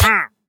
Minecraft Version Minecraft Version 1.21.4 Latest Release | Latest Snapshot 1.21.4 / assets / minecraft / sounds / mob / wandering_trader / hurt4.ogg Compare With Compare With Latest Release | Latest Snapshot
hurt4.ogg